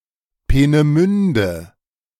Peenemünde (German pronunciation: [peːnəˈmʏndə]